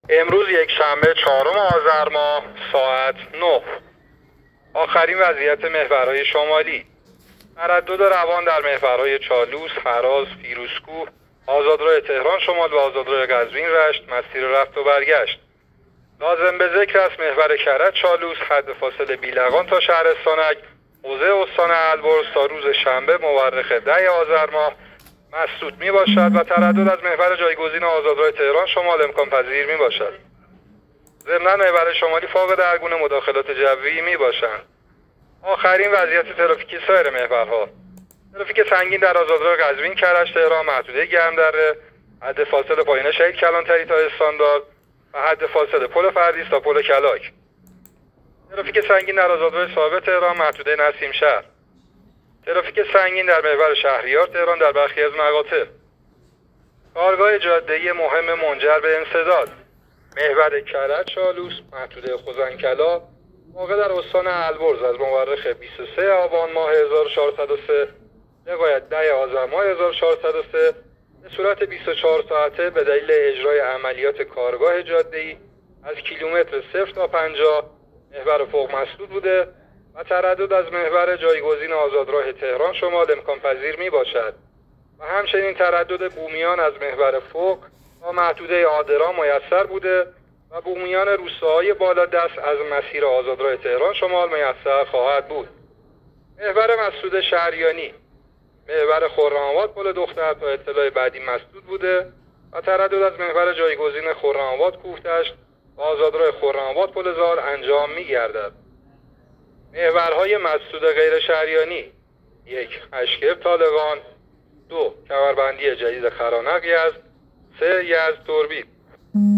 گزارش رادیو اینترنتی از آخرین وضعیت ترافیکی جاده‌ها تا ساعت ۹ چهارم آذر؛